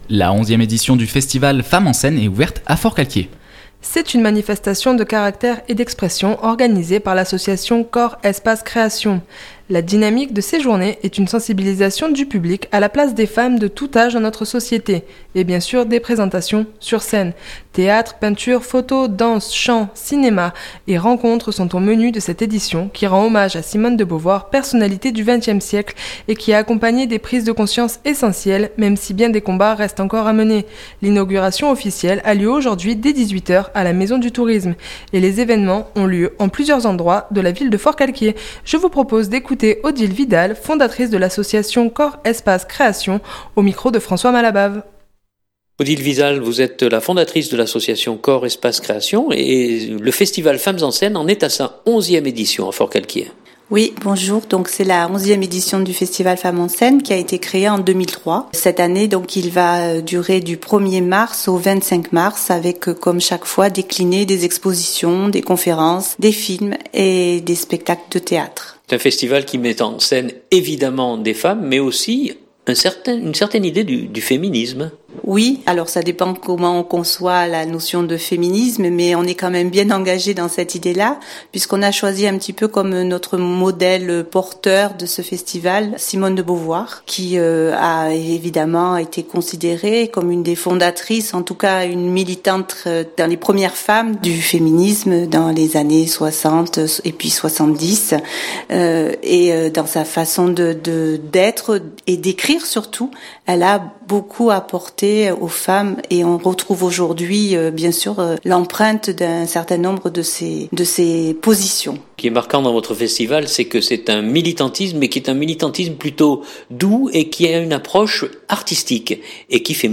Journal du 2017-03-03 Festival Femmes en scène.mp3 (9.32 Mo)